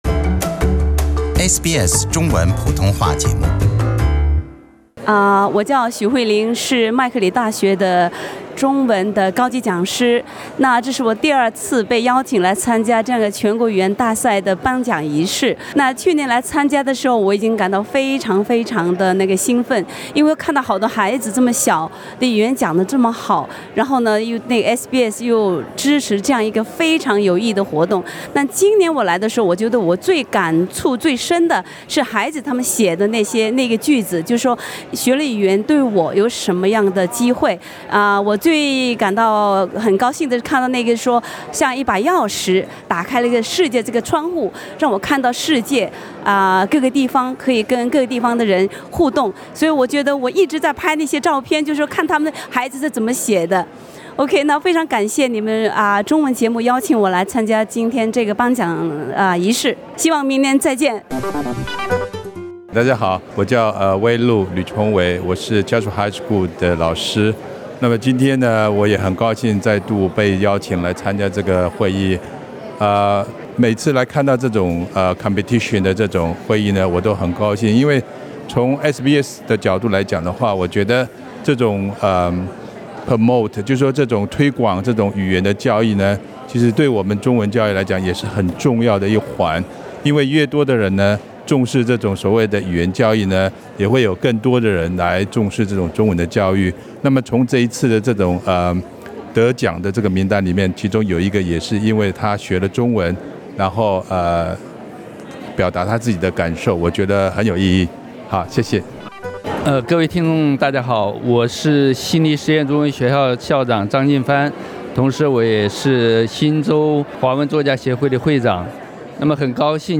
请听老师们的心声。